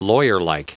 Prononciation du mot lawyerlike en anglais (fichier audio)
Prononciation du mot : lawyerlike